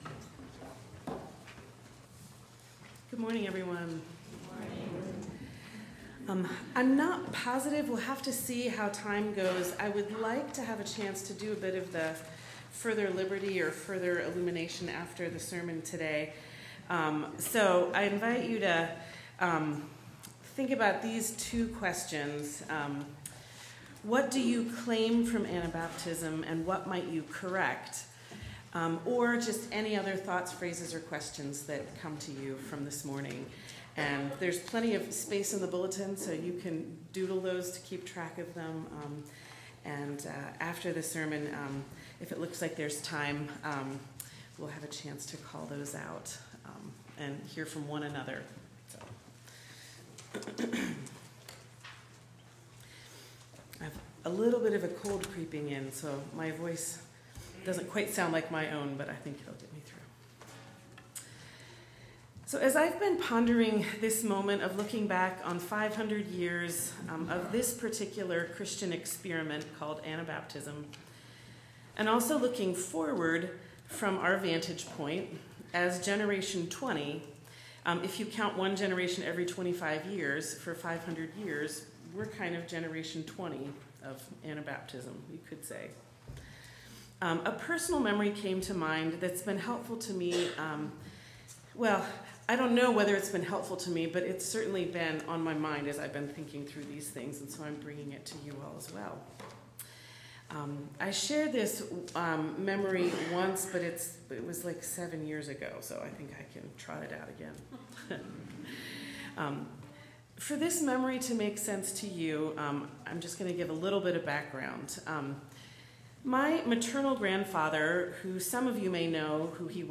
1/26/25 Sermon